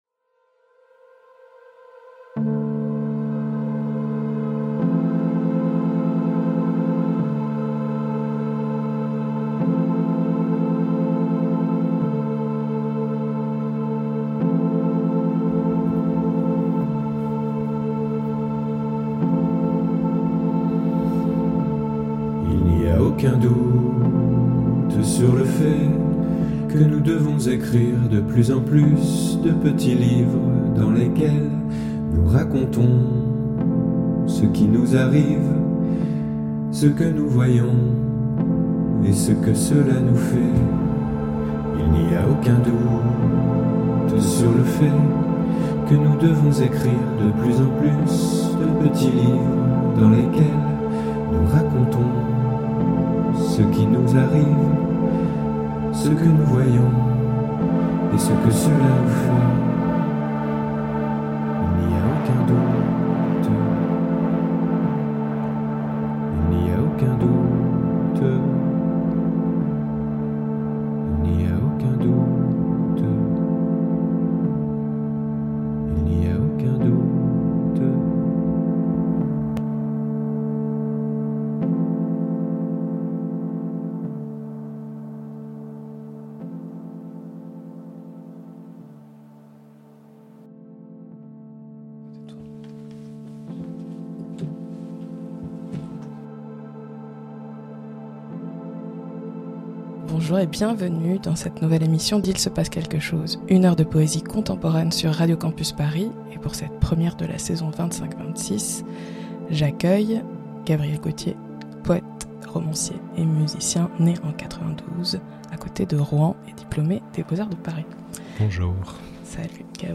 On a très longtemps, alors il y aura deux épisodes et du temps pour les textes et les chansons.
Entretien